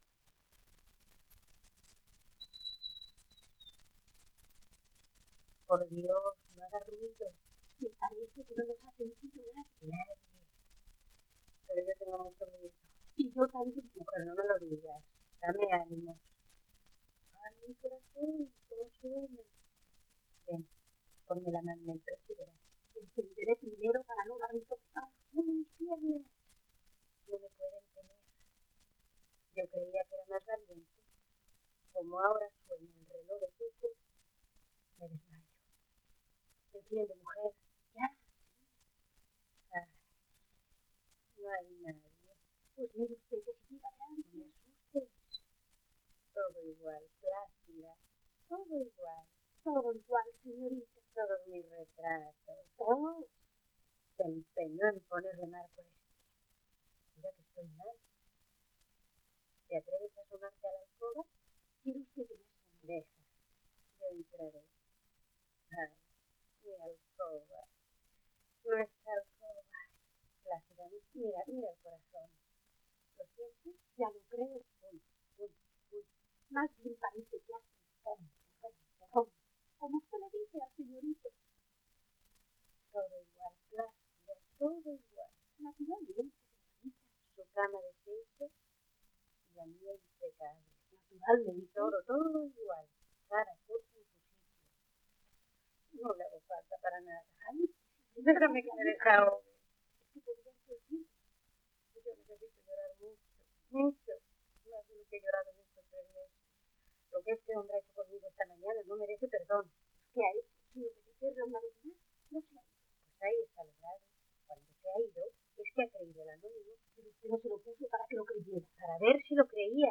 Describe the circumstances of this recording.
Tambor y cascabel, escena acto III (sonido remasterizado) 6 discos : 78 rpm ; 25 cm Intérprete